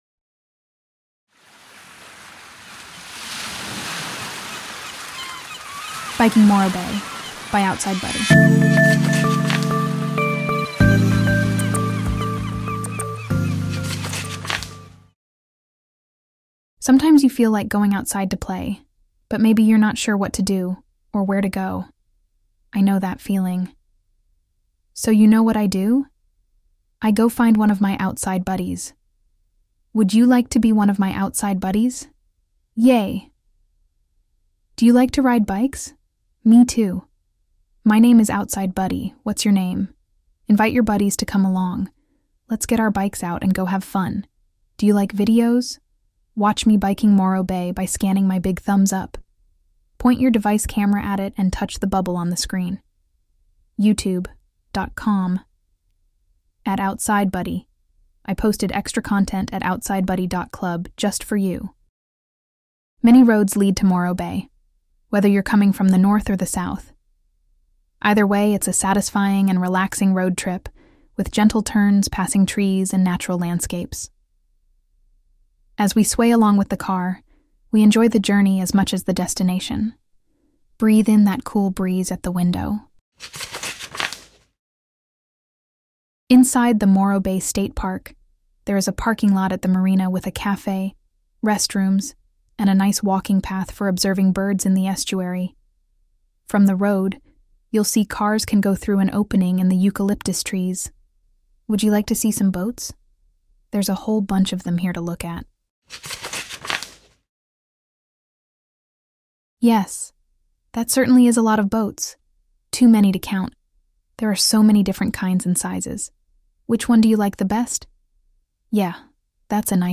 Free AudioBook!